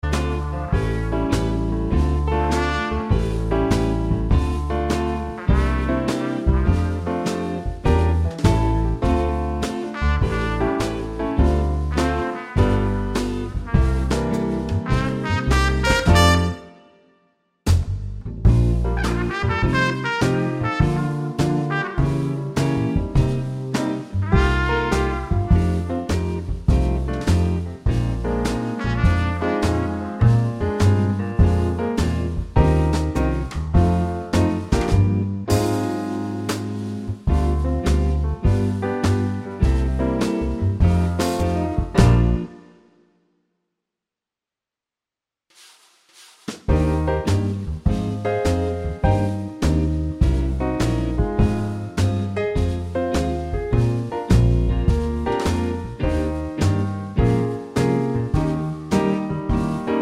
Minus Trumpet Solos Jazz / Swing 4:39 Buy £1.50